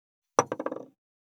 197,テーブル等に物を置く,食器,グラス,コップ,工具,小物,雑貨,コトン,トン,ゴト,ポン,
効果音物を置く